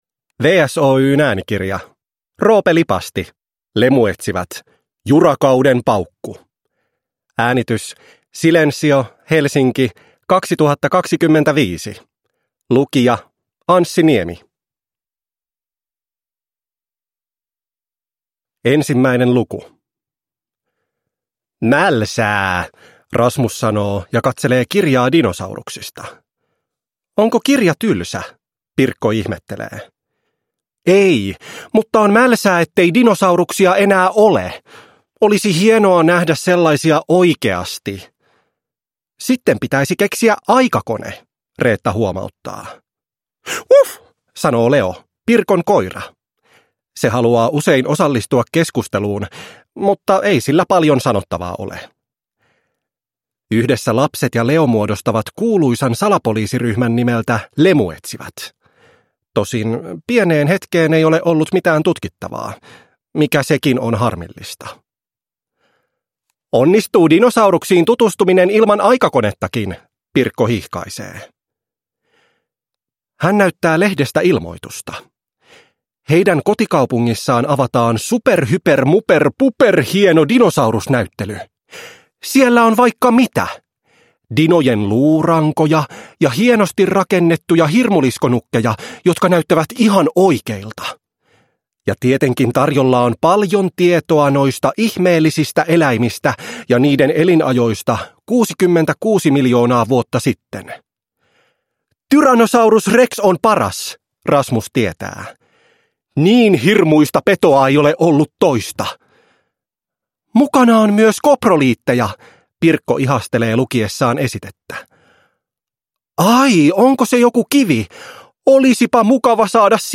Lemuetsivät: Jurakauden paukku – Ljudbok